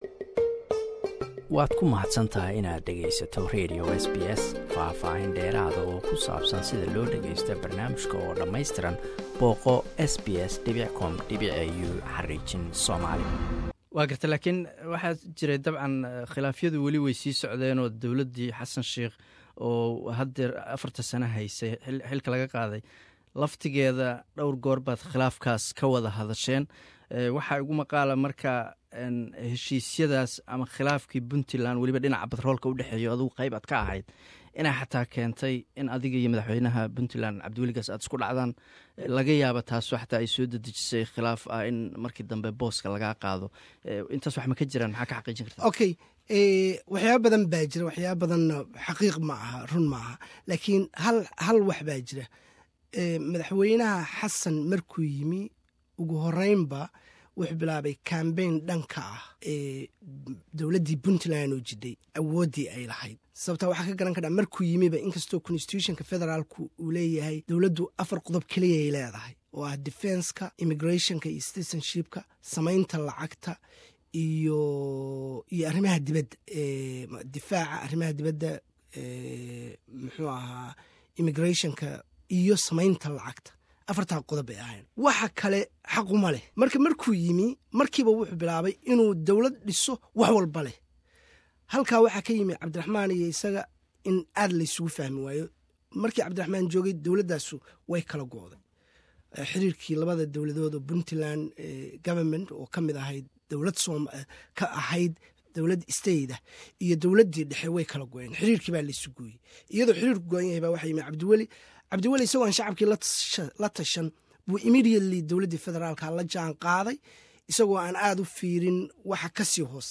Waraysi: Agaasimihii hore ee hayda macdanta iyo batroolka Puntland Q 2aad.